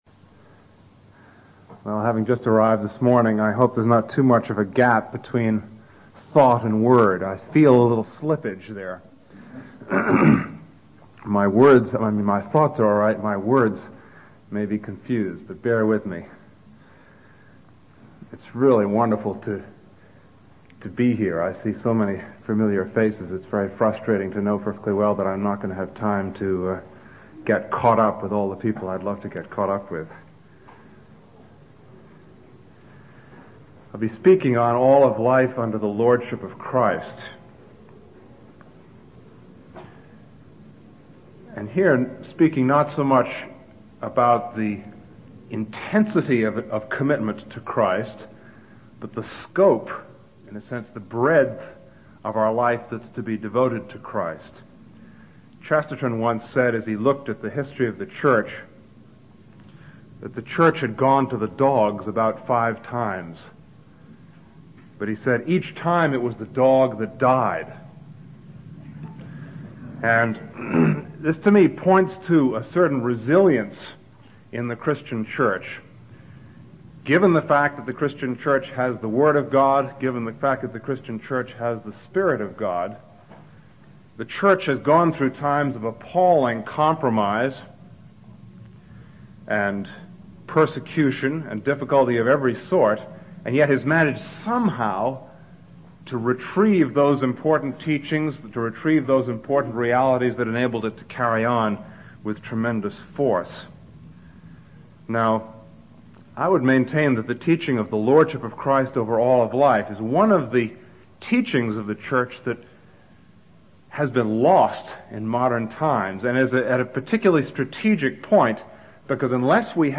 In this lecture